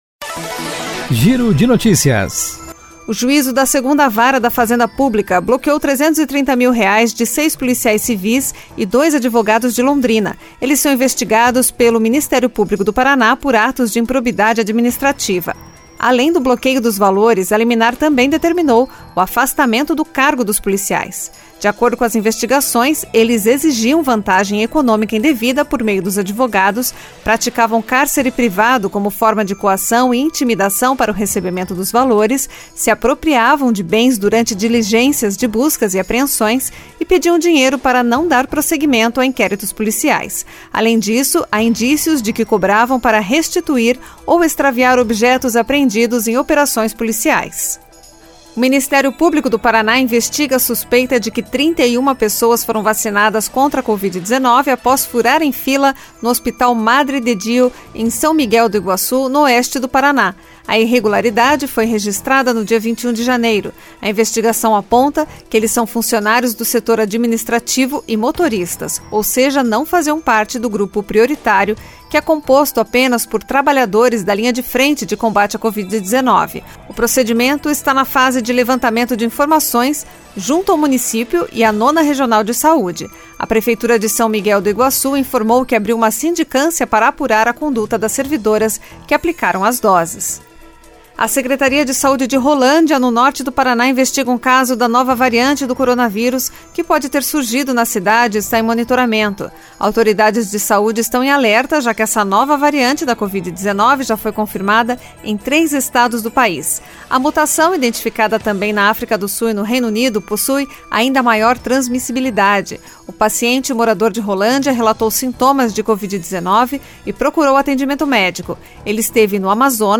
Giro de Noticias Tarde COM TRILHA